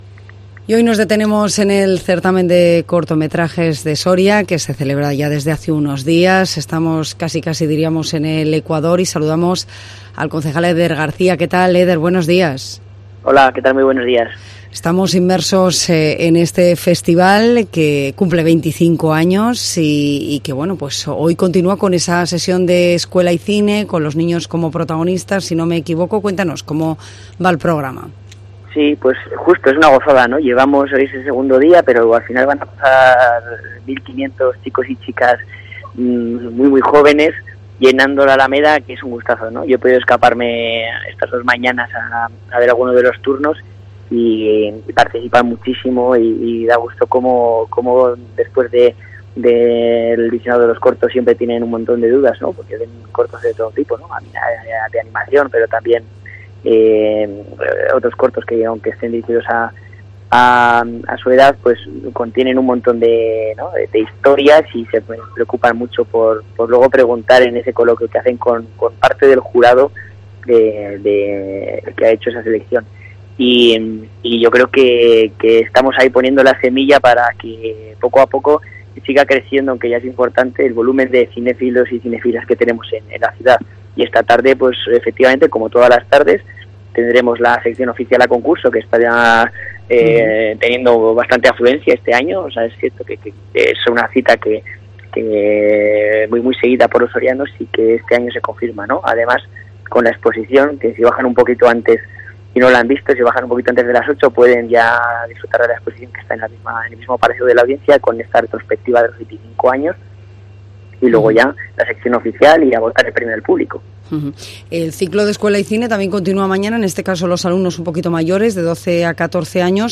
Eder García, concejal de Juventud de Soria, habla en COPE del XXV Certamen de Cortos